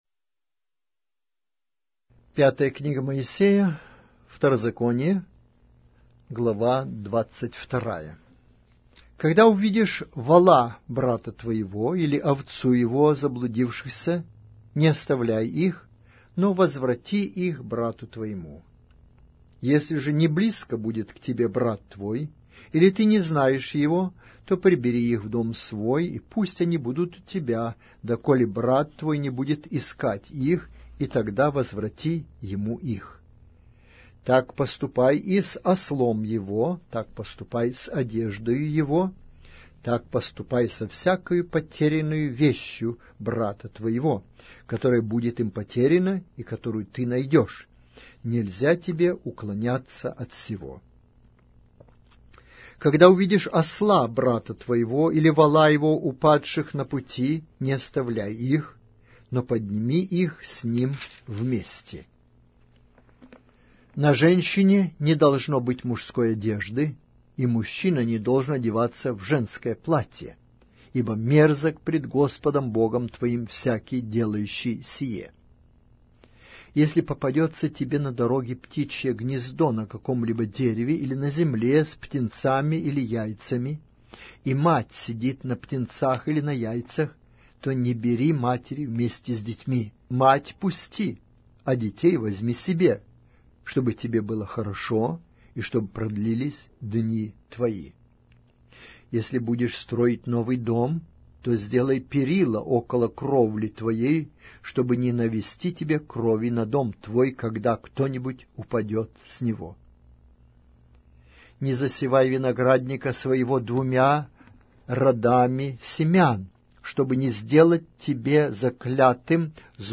Аудиокнига: Книга 5-я Моисея. Второзаконие